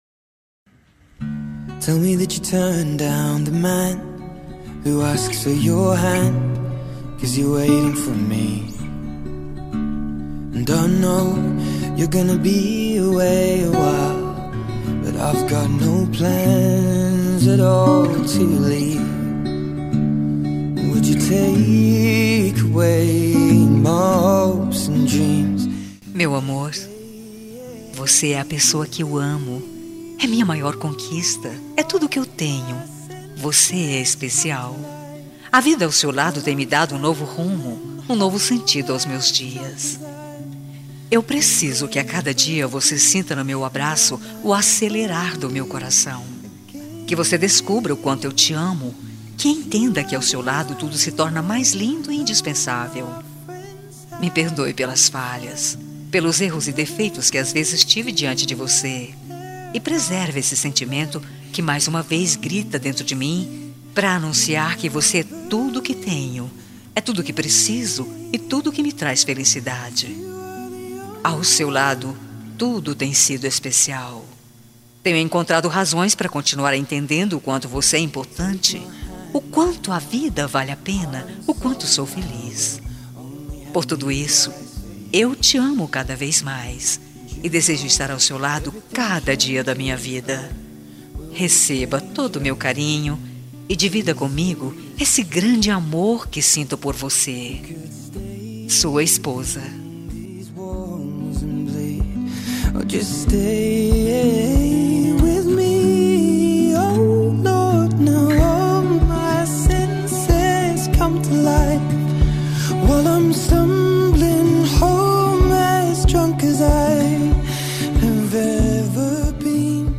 Telemensagem Romântica para Marido – Voz Feminina – Cód: 9066